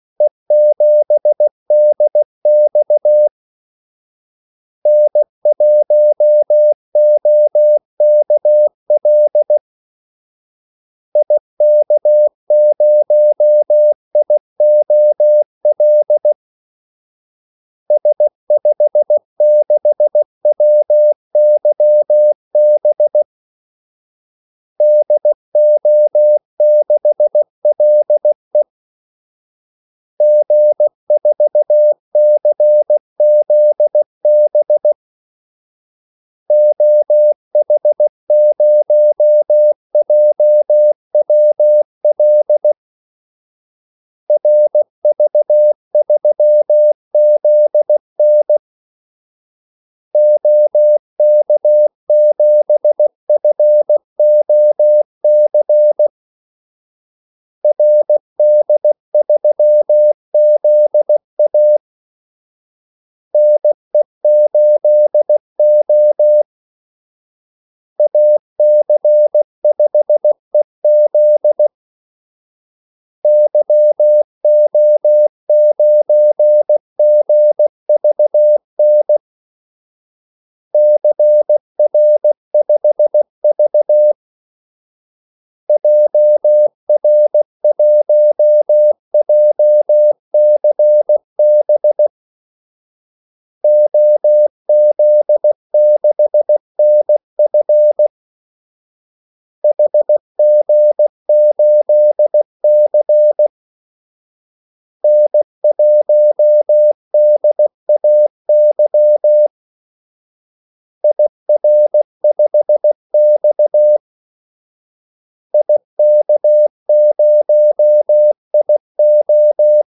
Kaldesignaler 16 wpm | CW med Gnister
Callsigns-16wpm.mp3